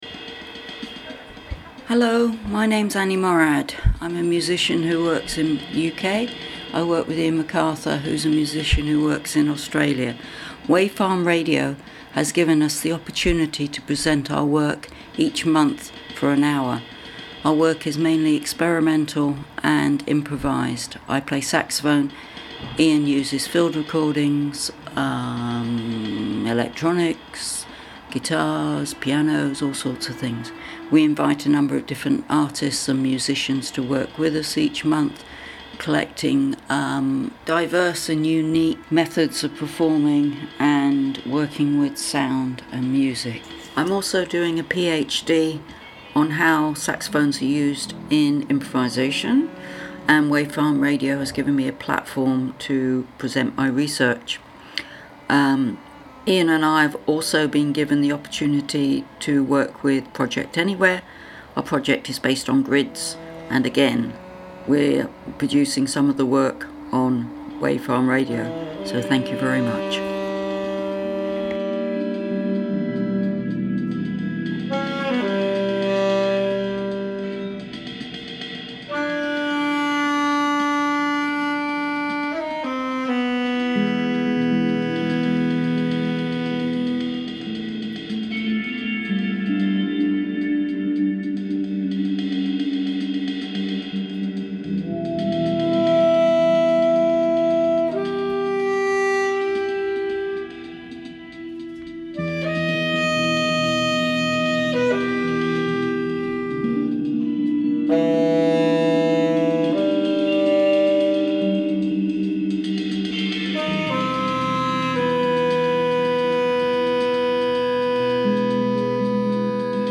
improvised piece
guitar and porcelain
tenor sax
field recordings, and electronics